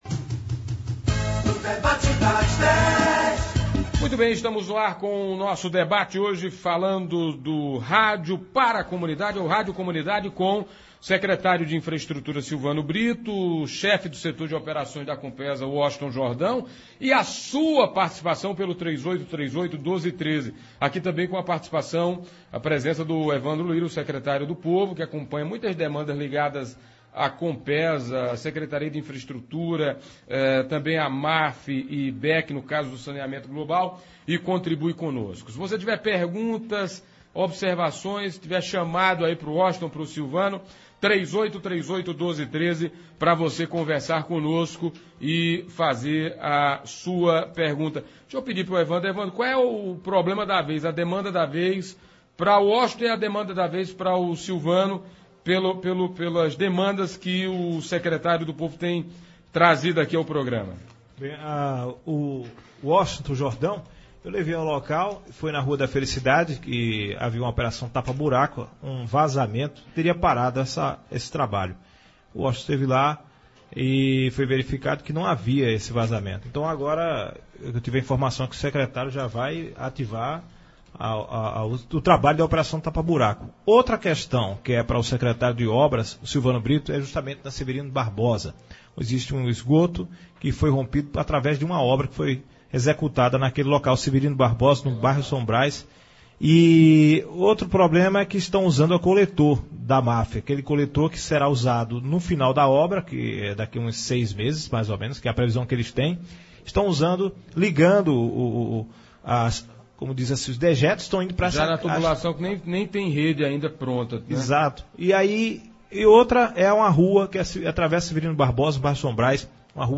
Ouça abaixo na íntegra o debate e dê a sua opinião: